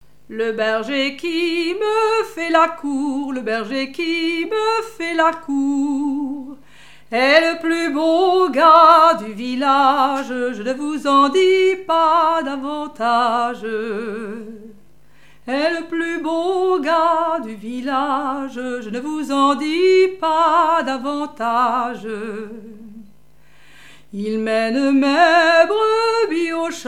danse : ronde : demi-rond
Genre laisse
Pièce musicale inédite